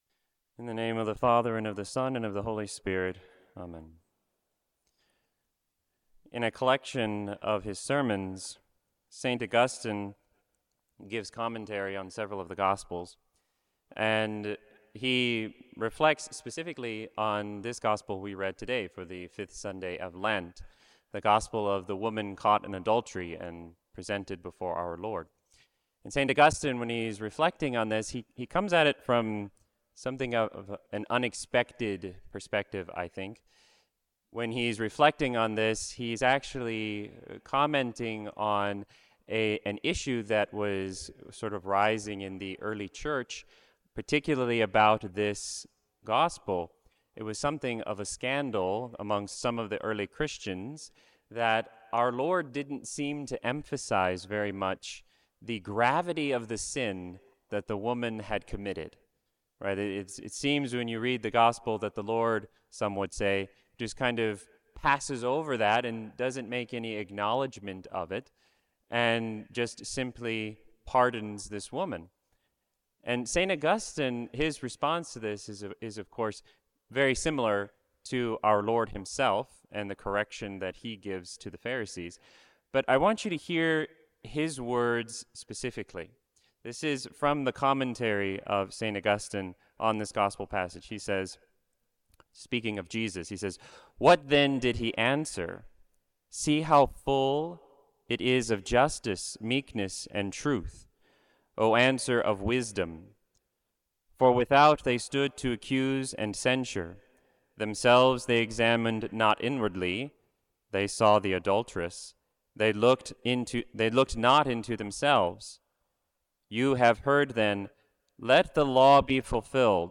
Homily
at the Chapel of Divine Mercy in Auburn, KY at the 10:00am Mass.